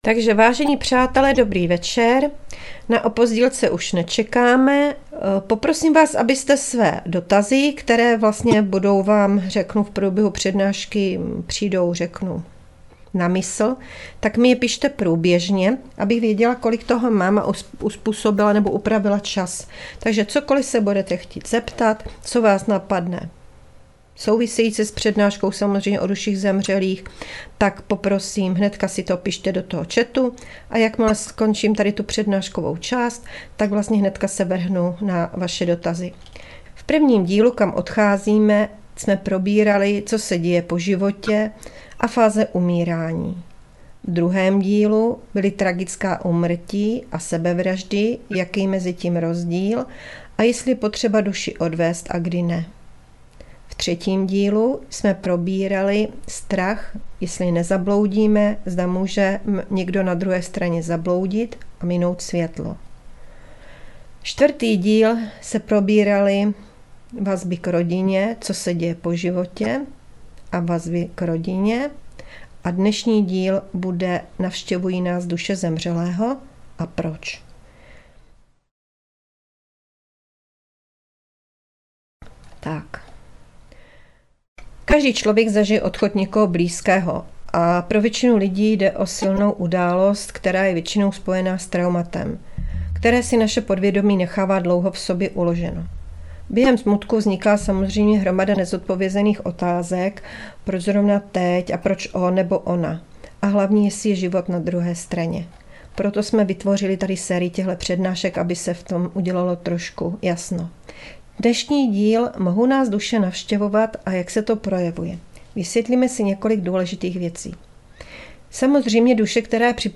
Přednáška Duše zemřelých, díl 5. - Navštěvují nás duše zemřelého?